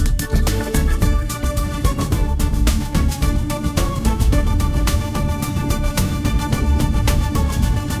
audio-to-audio music-generation